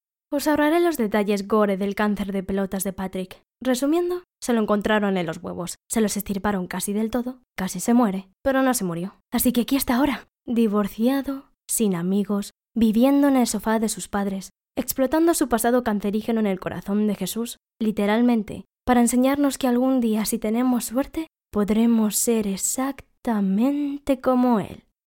Locutora y actriz de doblaje.
Voz jóven, cercana y natural.
kastilisch
Sprechprobe: Sonstiges (Muttersprache):
Young, close and natural voice.